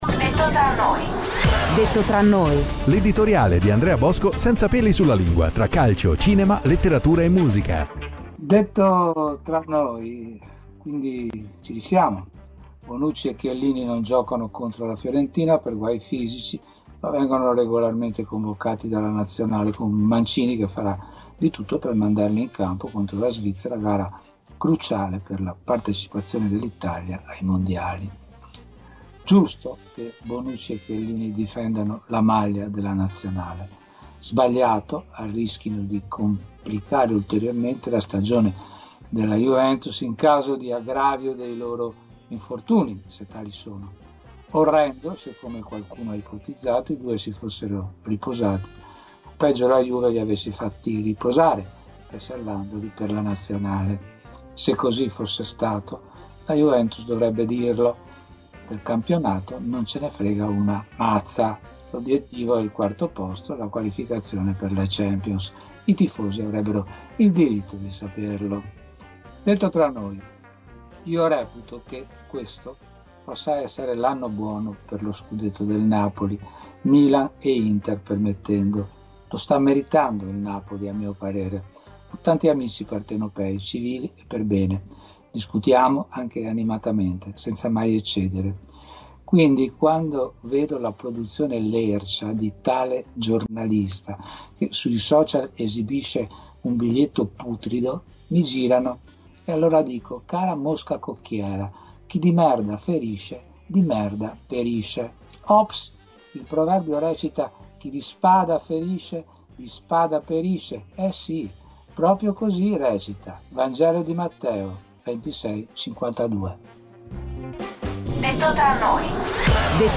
"DETTO TRA NOI", l'editoriale